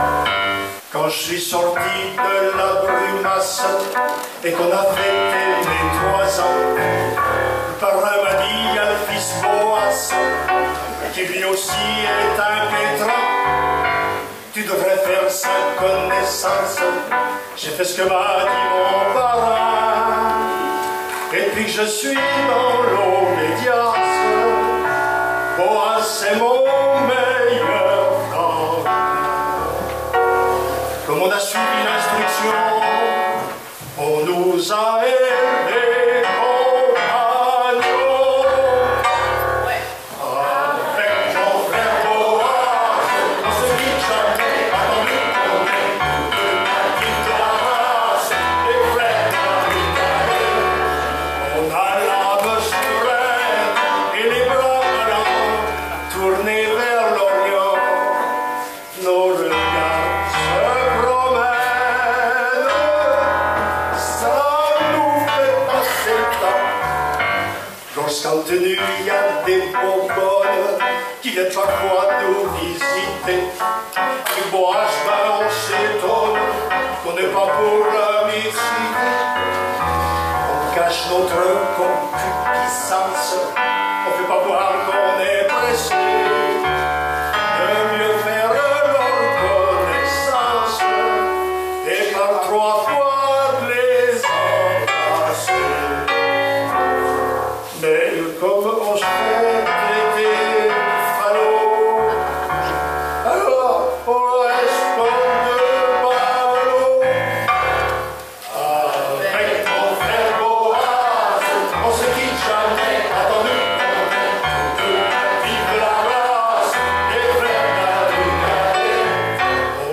Enregistrement public Festival d’Humour 2013